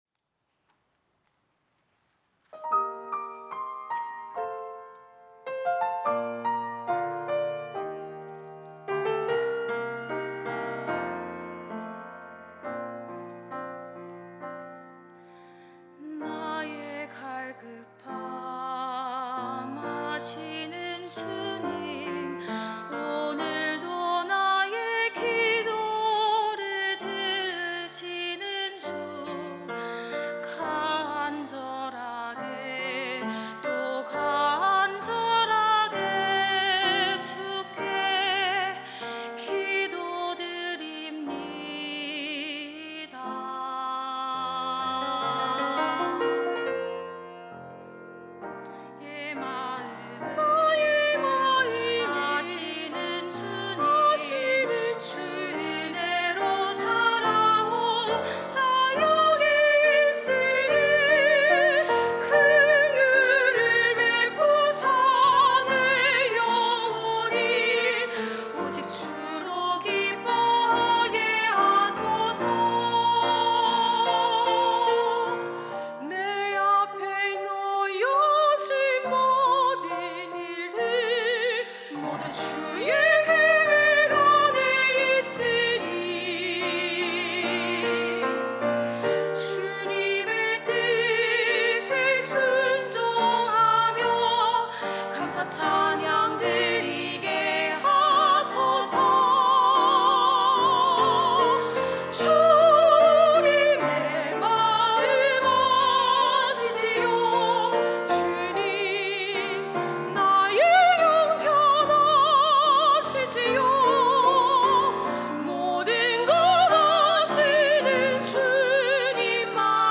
특송과 특주 - 주님만이 나의 전부입니다